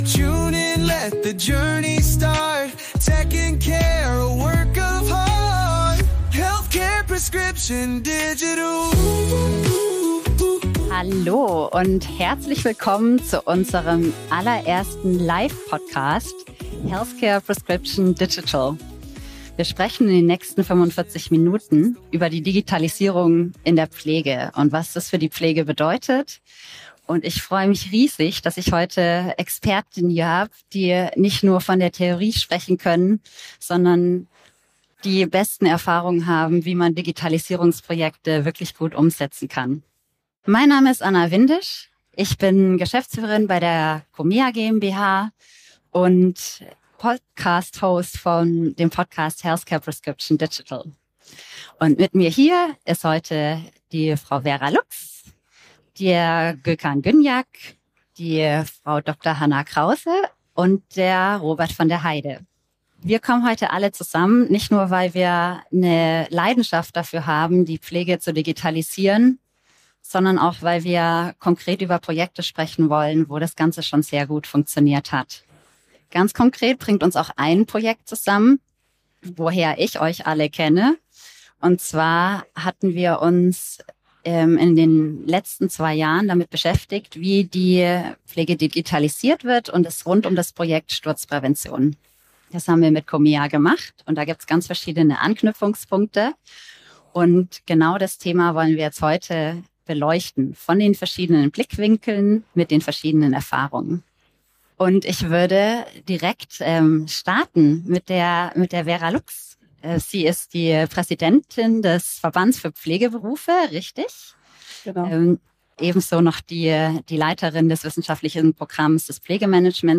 Live-Podcast auf der DMEA 2025 in Berlin